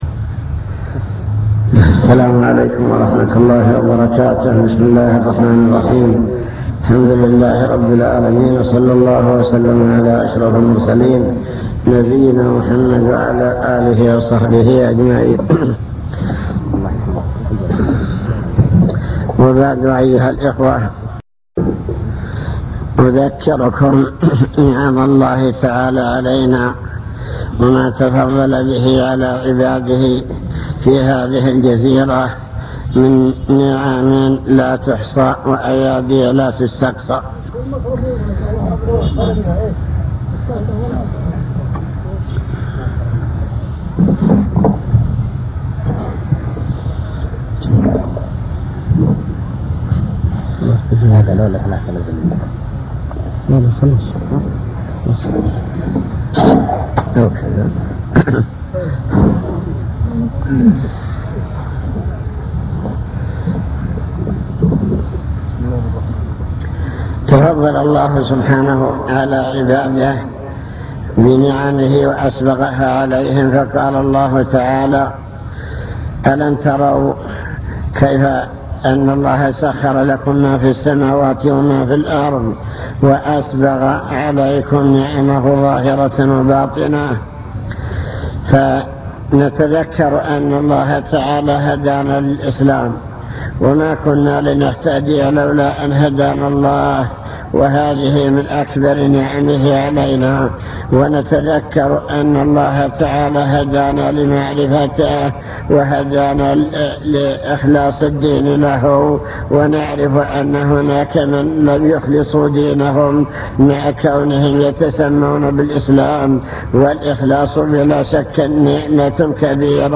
المكتبة الصوتية  تسجيلات - محاضرات ودروس  محاضرة في بني زيدان